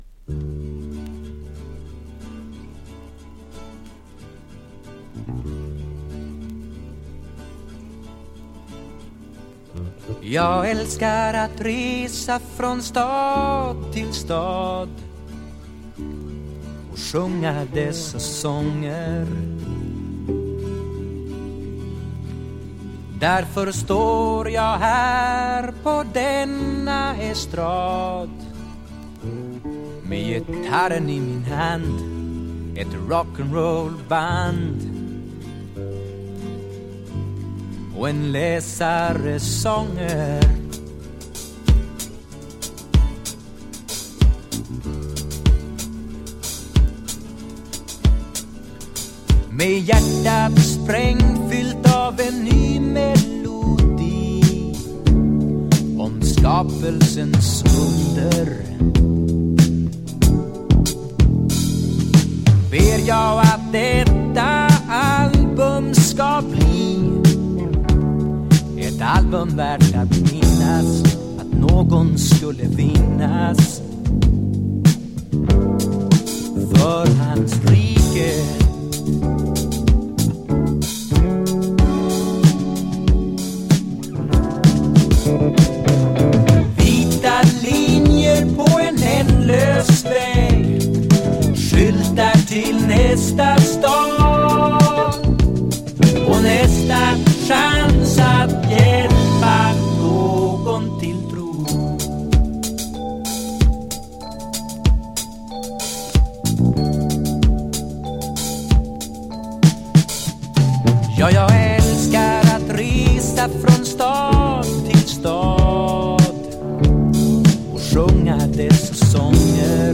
盤に多少スレが見られますが音への影響は軽微です（試聴にてご確認ください）。